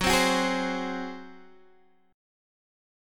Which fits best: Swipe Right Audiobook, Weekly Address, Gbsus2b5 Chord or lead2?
Gbsus2b5 Chord